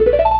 maximize.wav